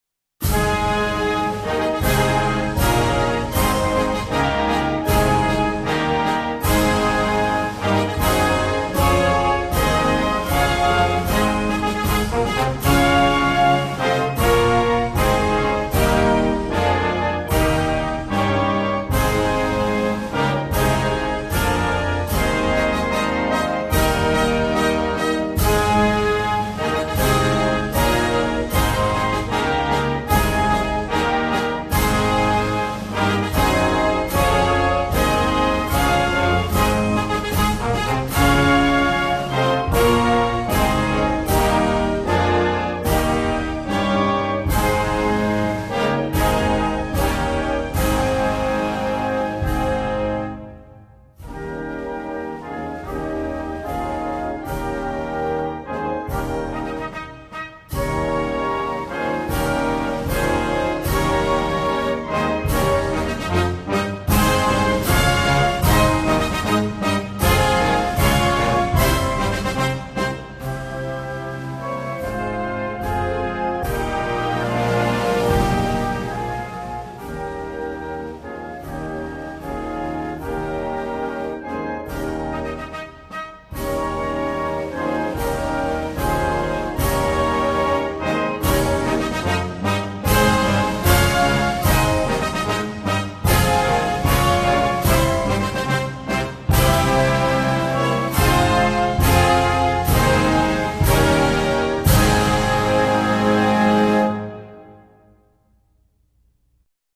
Tautiška_giesme_instrumental.mp3